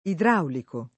vai all'elenco alfabetico delle voci ingrandisci il carattere 100% rimpicciolisci il carattere stampa invia tramite posta elettronica codividi su Facebook idraulico [ idr # uliko ; non - 2 liko ] agg. e s. m.; pl. m. ‑ci